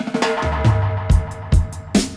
141-FILL-FX.wav